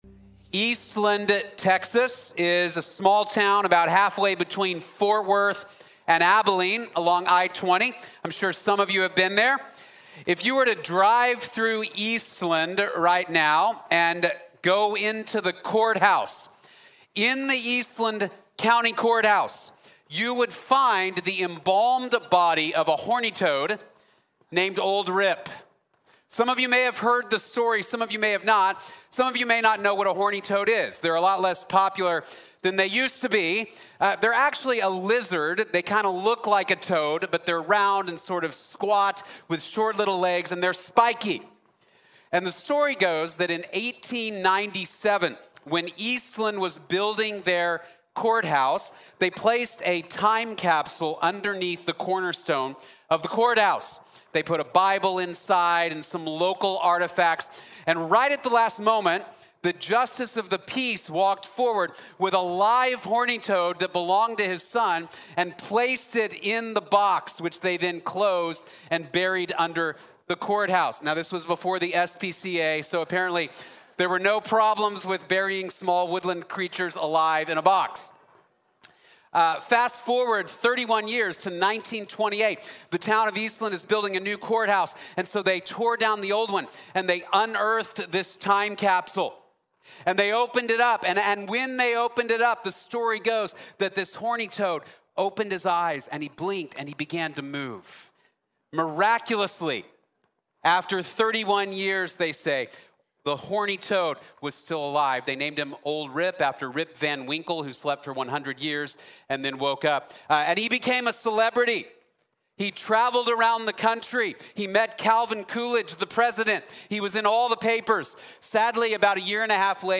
The Empty Tomb Changes Everything | Sermon | Grace Bible Church